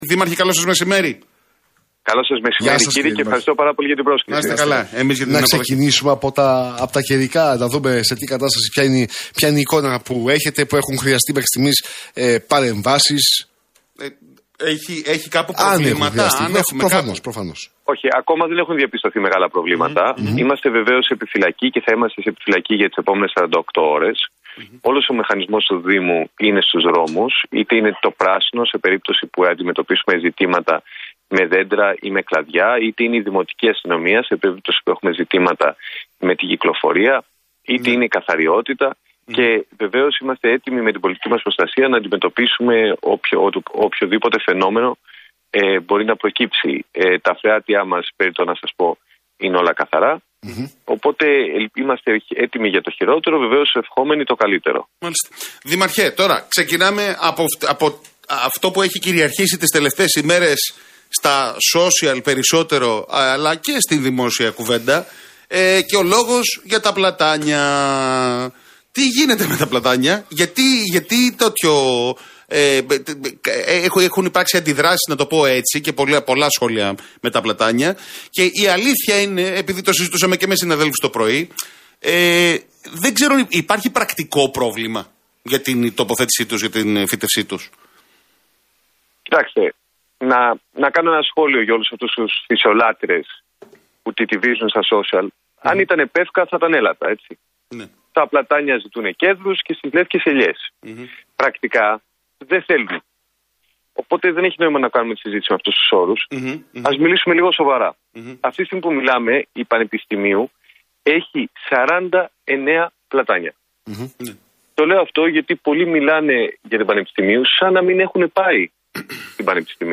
Μπακογιάννης στον RealFm 97,8: Μεγάλο στοίχημα είναι να συνδέσουμε το Σύνταγμα με την Ομόνοια